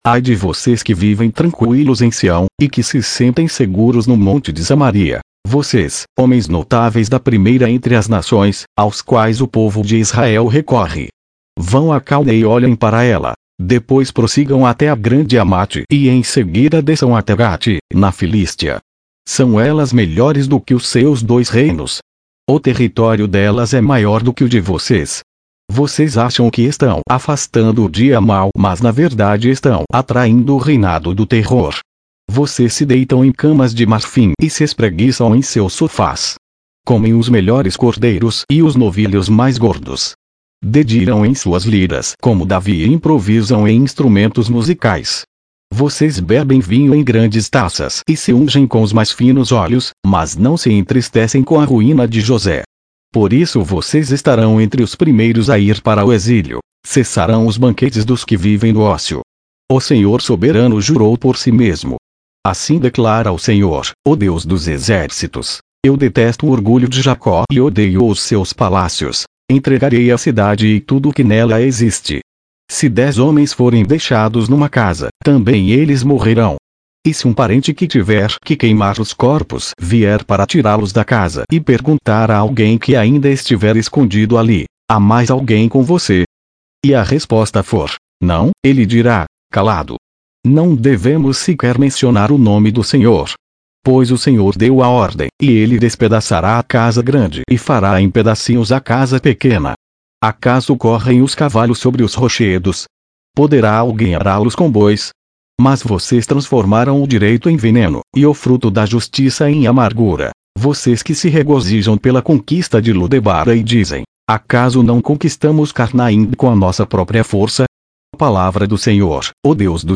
Leitura na versão Nova Versão Internacional - Português